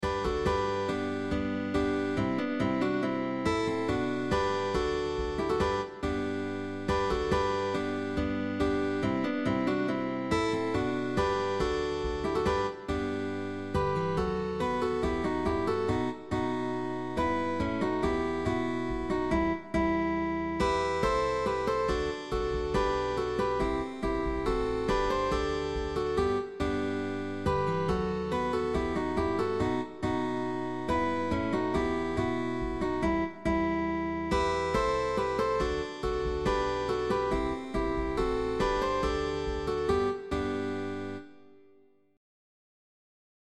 Guitar quartet sheetmusic.
GUITAR QUARTET
Tag: Baroque